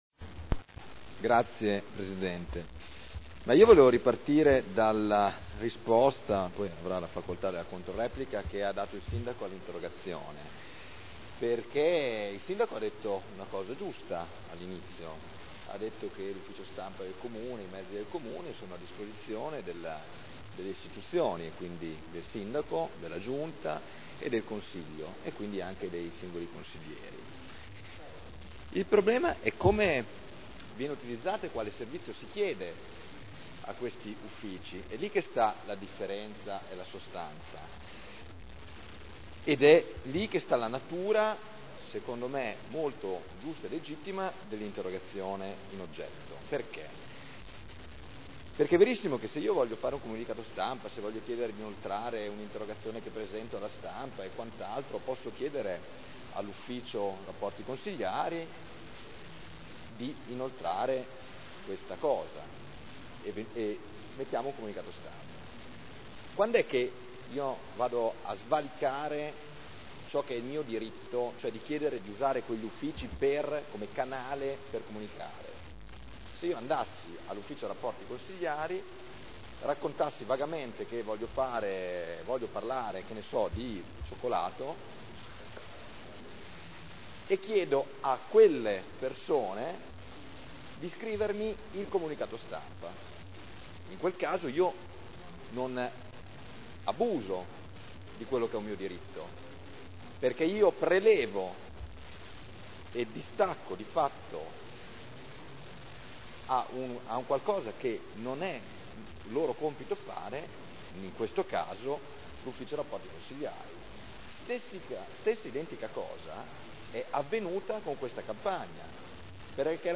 Michele Barcaiuolo — Sito Audio Consiglio Comunale
Seduta del 3/02/2011.